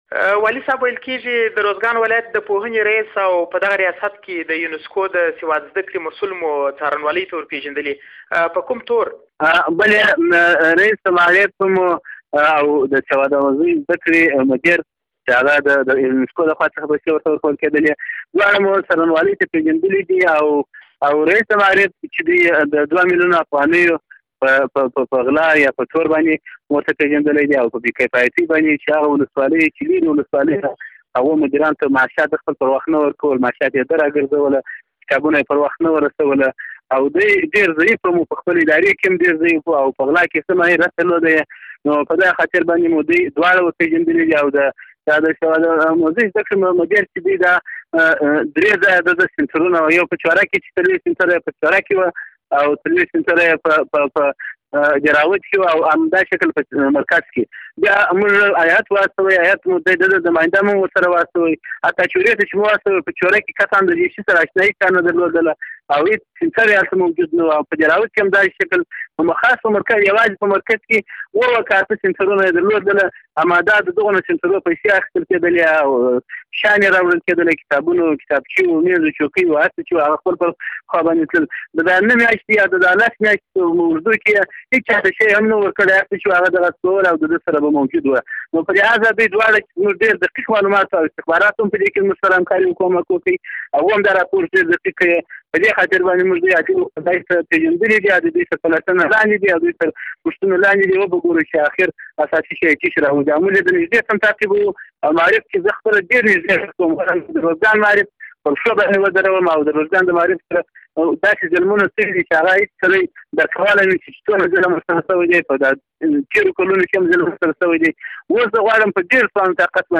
د ارزګان له والي سره مرکه